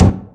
1 channel
giant_ball03.mp3